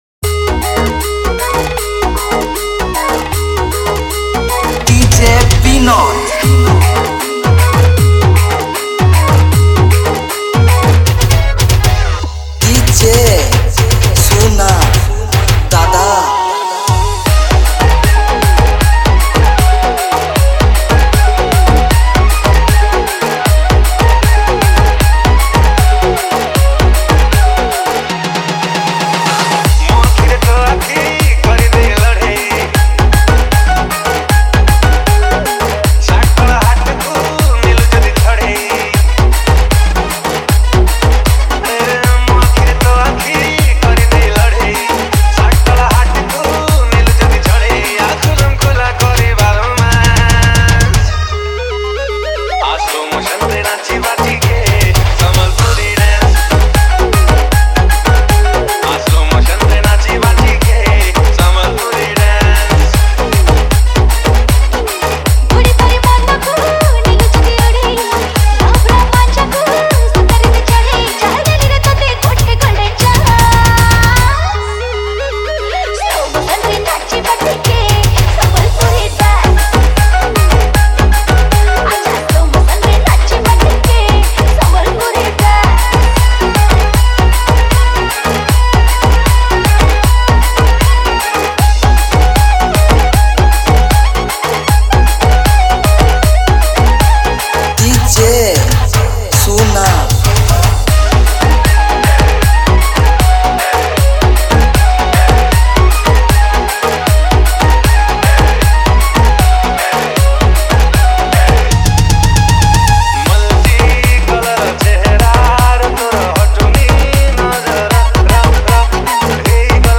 Sambalpuri Dj Song 2024
Category:  Odia New Dj Song 2019